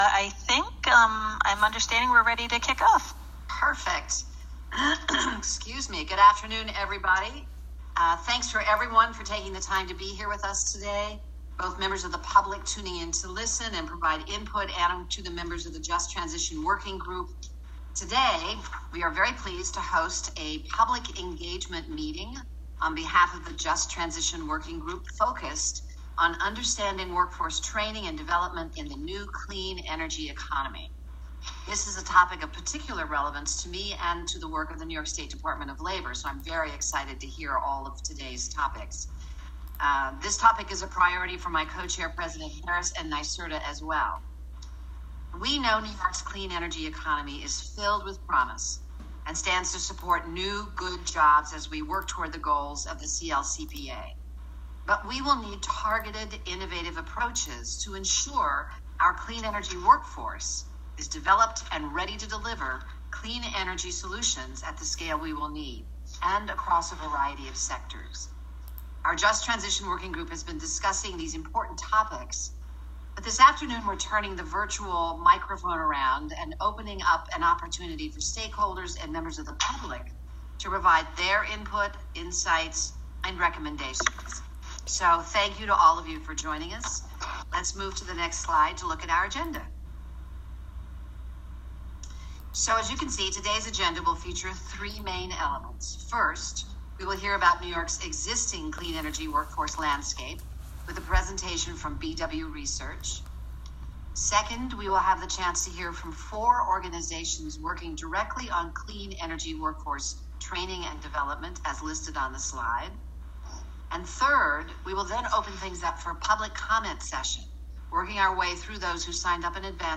“Climate Action Council“ Listen to a recording of the meeting